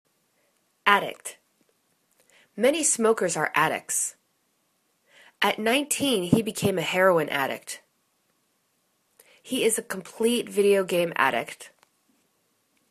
ad.dict     /'adikt/    n